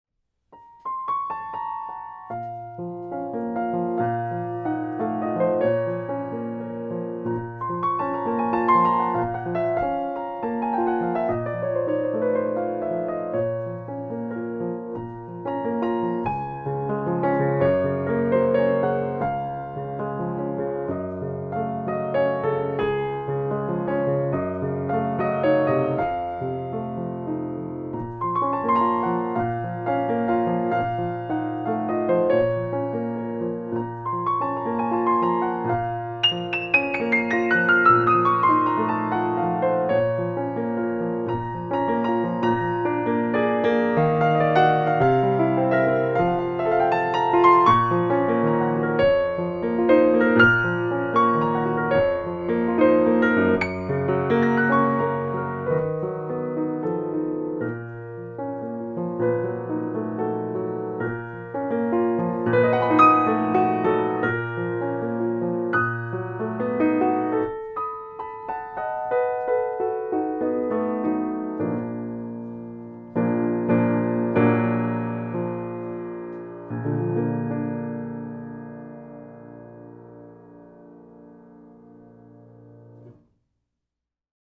A recent model, Steinway-designed baby grand player piano
With a clear, singing tone and a medium-light action, it is very expressive and fun to play.  The treble in particular is absolutely outstanding.
Quality microphones were used to make the recording, and the audio is minimally processed so that you can get a good sense of the actual sound of the piano.